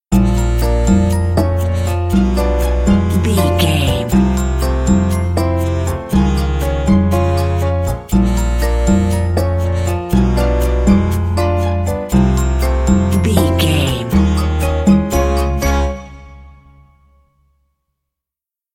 Aeolian/Minor
piano
percussion
flute
silly
circus
goofy
comical
cheerful
perky
Light hearted
quirky